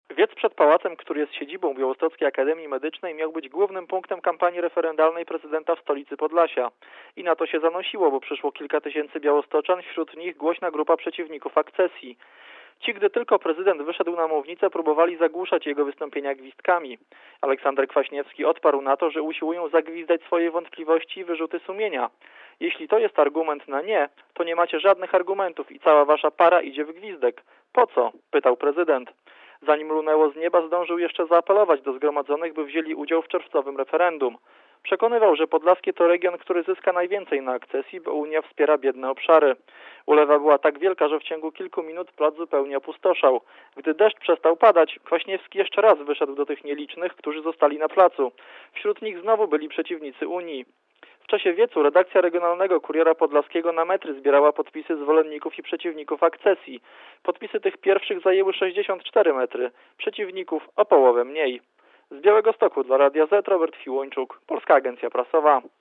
Korespondencja z Białegostoku (515Kb)
Od początku wystąpienie prezydenta zakłócali gwizdkami przeciwnicy UE, którzy rozdawali też antyunijne ulotki Ligi Polskich Rodzin.